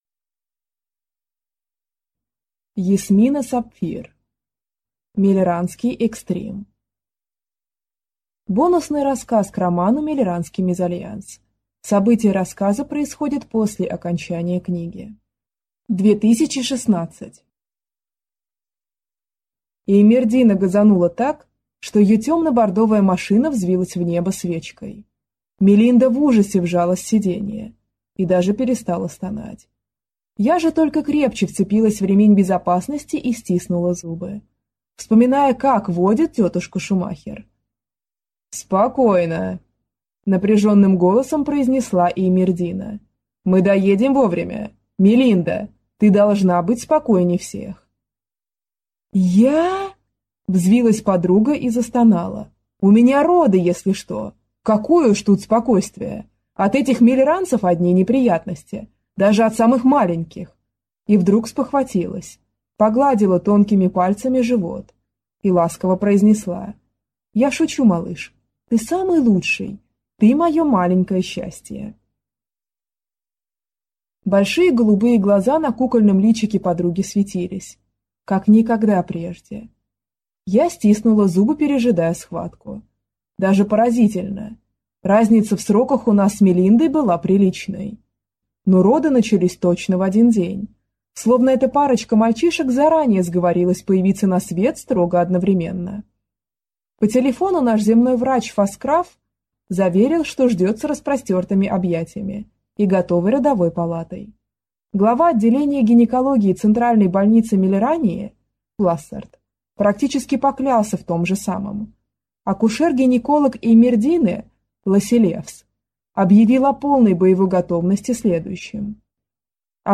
Аудиокнига Мельранский экстрим | Библиотека аудиокниг